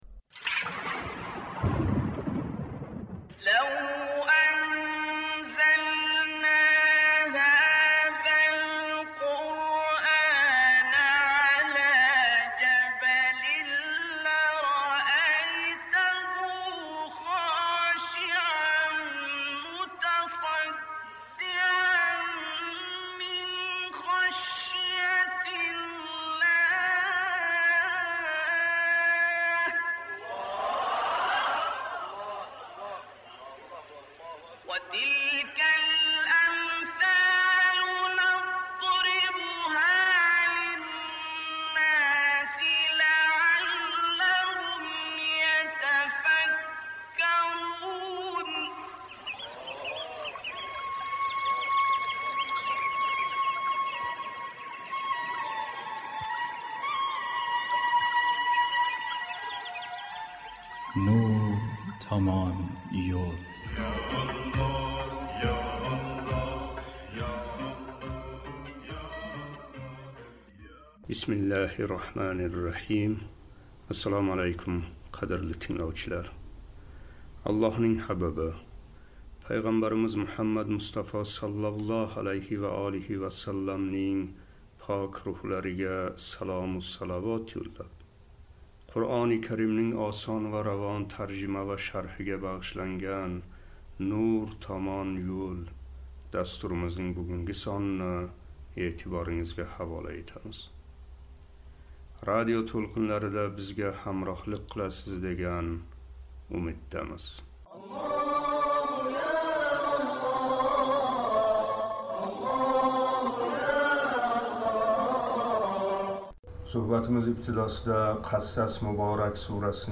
697қисм."Қасас" муборак сураси,56-58ояти карималар Суҳбатимиз ибтидосида “Қасас” муборак сураси 56-ояти каримасининг тиловатига қулоқ тутамиз.